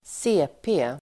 Ladda ner uttalet
CP förkortning, CP Uttal: [²s'e:pe:] Definition: cerebral pares (cerebral palsy) Förklaring: En (medfödd) hjärnskada som leder till bl a rörelse- och talhinder.